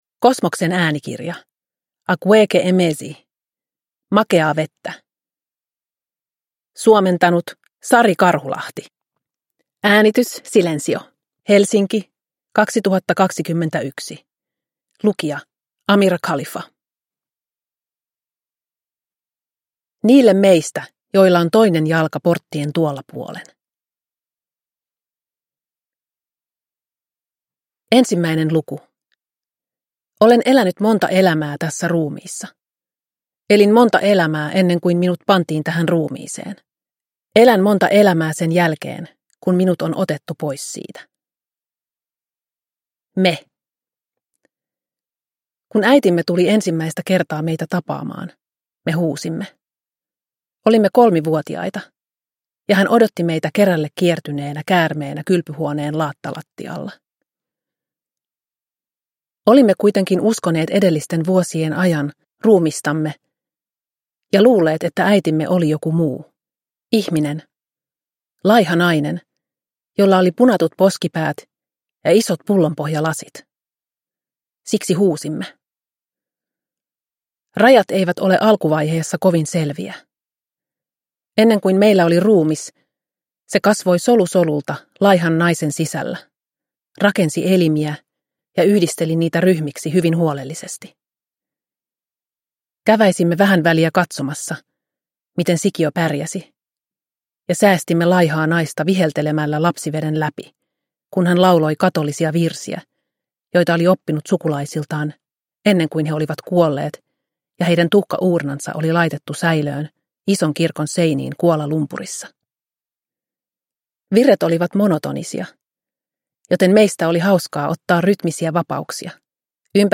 Makeaa vettä – Ljudbok – Laddas ner